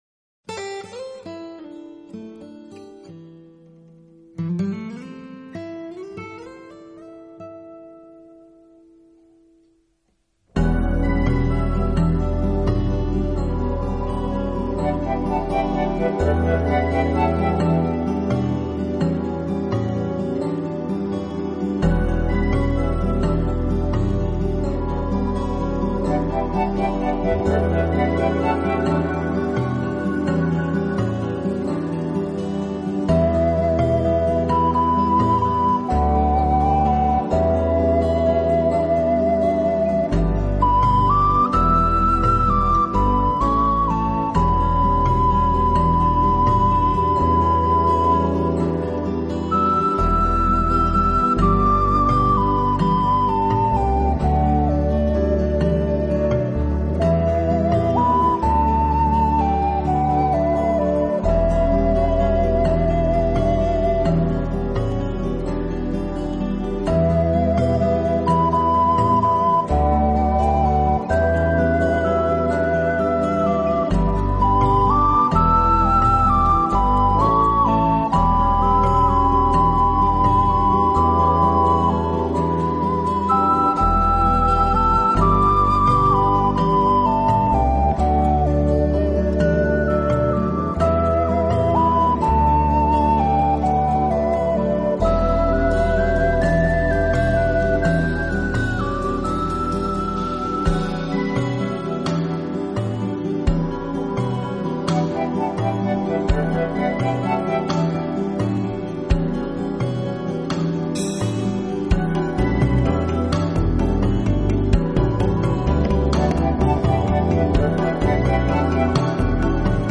陶笛专辑
感觉象是在寂静的林中有一个人在和你说话，深情的、快乐的、充满希望的旋律在你四周飘